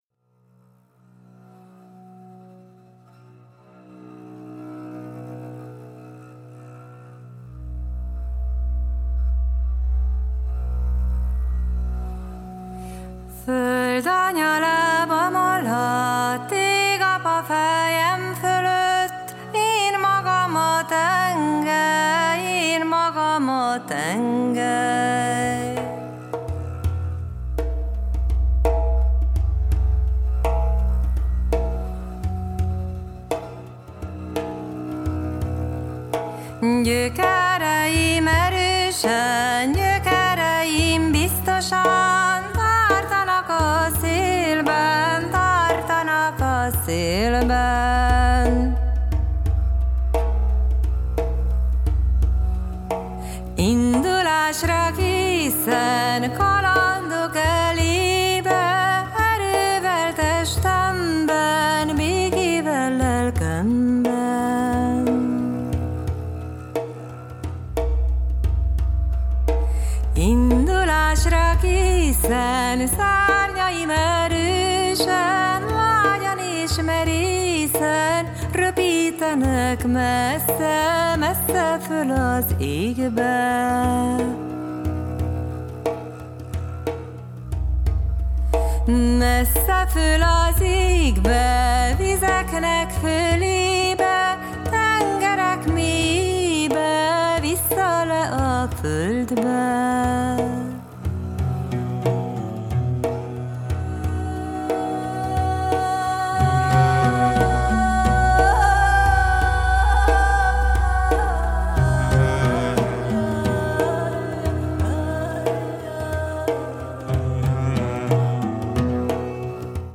“グルーヴ＆ボイス・トリオ”
音数少なめのアンサンブルで構成されているサウンドの方も
程よい瞑想感も心地良いですね！